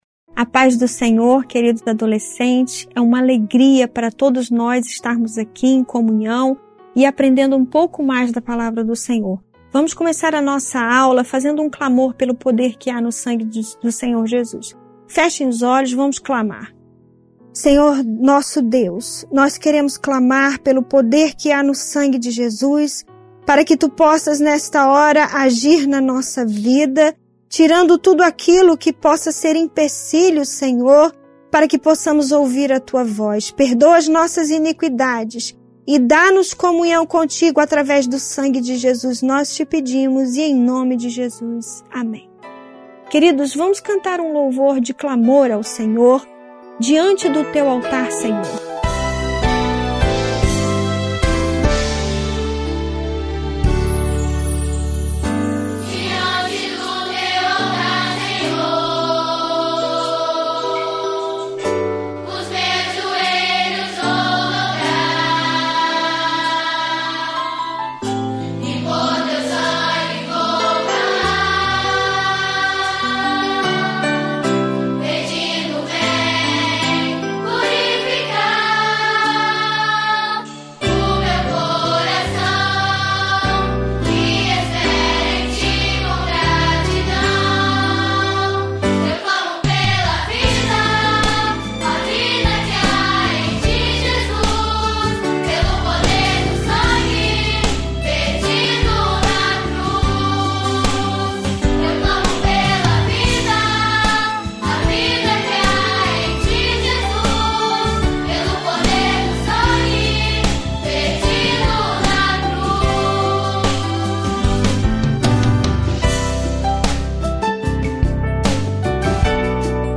Aula online divulgada pela Igreja Cristã Maranata no dia 07 de maio de 2020 para a classe de adolescentes